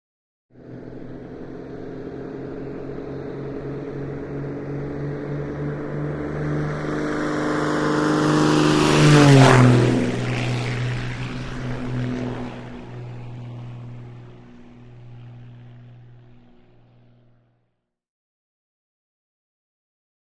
Beechcraft: By Medium; Beechcraft, 1978 E55 Baron. Drone In Distance, Approach And Scream By With Stereo Echoes, Short Away. Medium To Distant Perspective. Prop Plane.